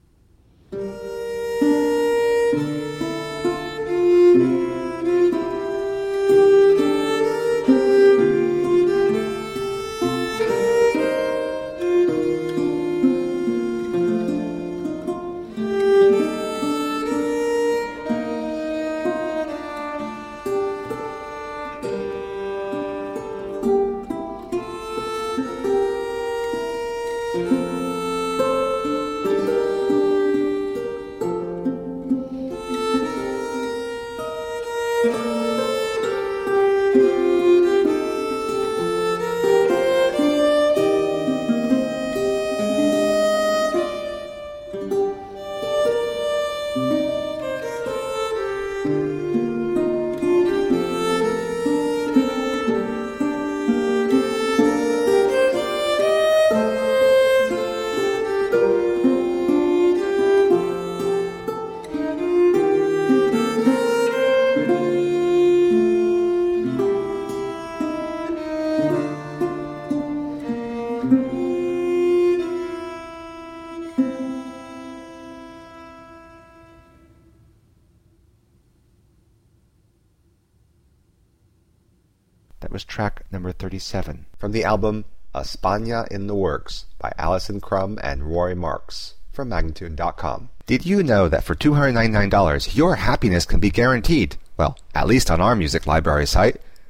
Classical, Renaissance, Instrumental
Lute, Viola da Gamba